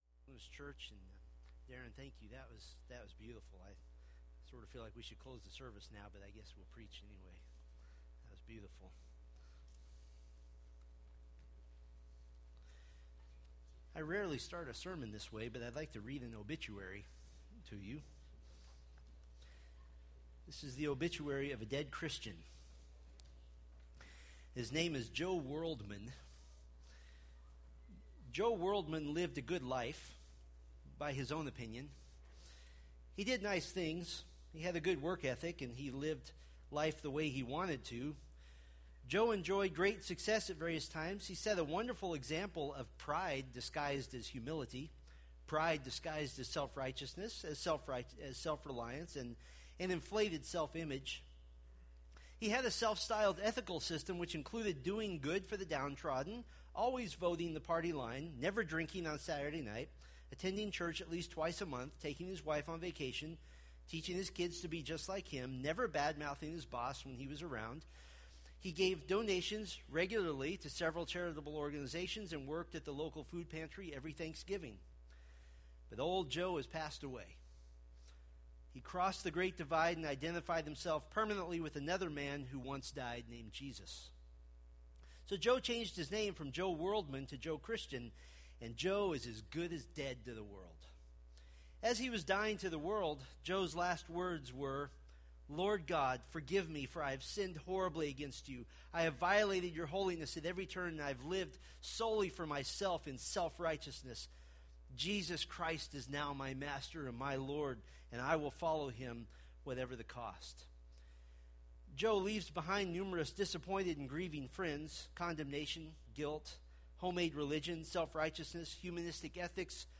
Colossians Sermon Series